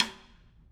Snare2-taps_v2_rr1_Sum.wav